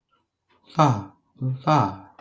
File:Voiceless alveolar lateral flap.wav - Wikipedia
Voiceless_alveolar_lateral_flap.wav